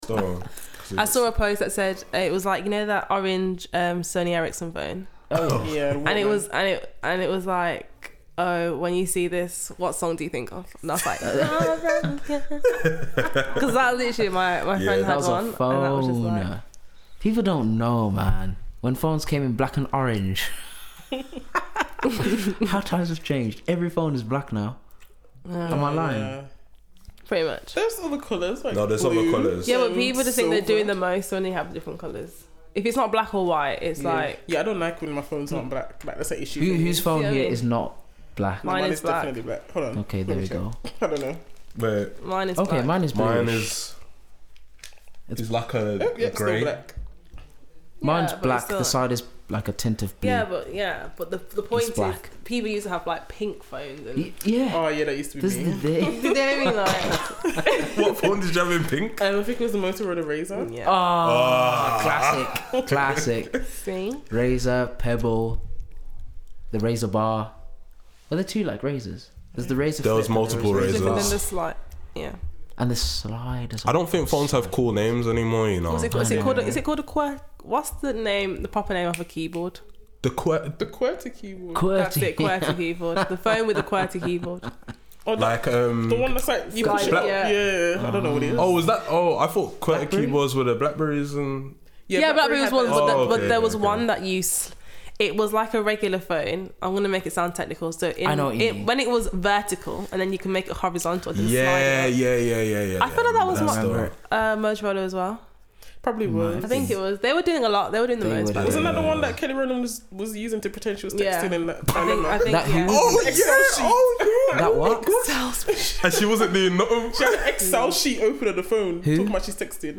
Just one of those conversations you have with your friends.